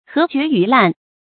河決魚爛 注音： ㄏㄜˊ ㄐㄩㄝˊ ㄧㄩˊ ㄌㄢˋ 讀音讀法： 意思解釋： 比喻事物壞到極點，不可收拾。